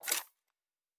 Weapon 11 Foley 1 (Rocket Launcher).wav